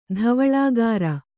♪ dhavaḷāgāra